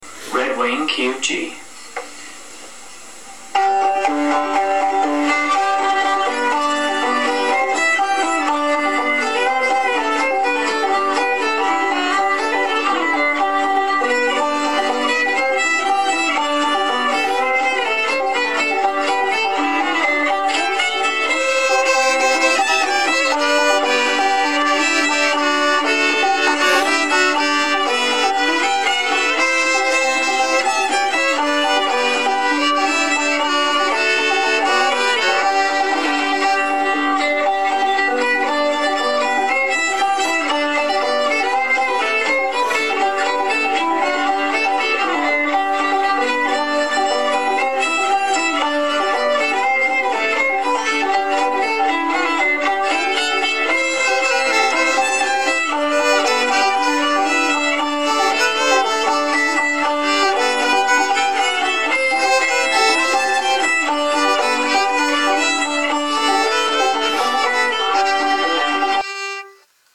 Red Wing Guitar Backing Track | Page 2 | Red Wing - Internationl Group Project | FORUM | LEARN TO PLAY THE VIOLIN FOR FREE!LEARN TO PLAY THE VIOLIN FOR FREE!